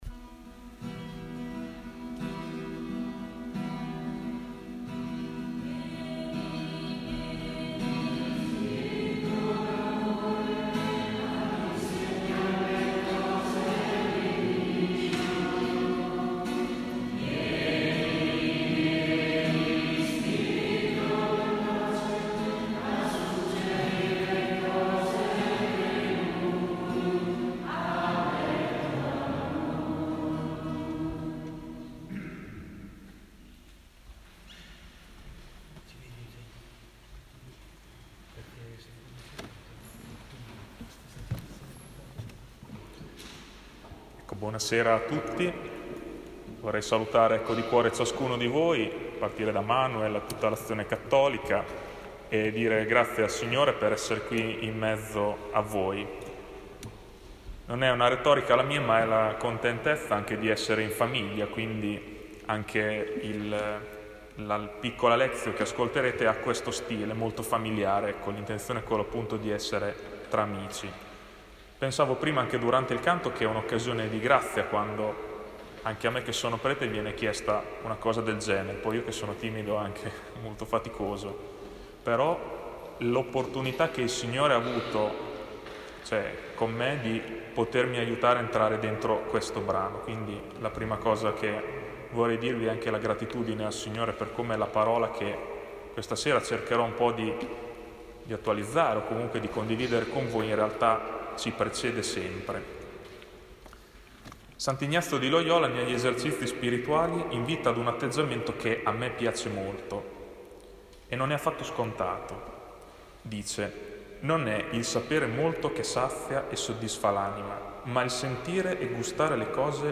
Si è svolto lunedì 17 settembre la lectio sull’icona biblica dell’anno.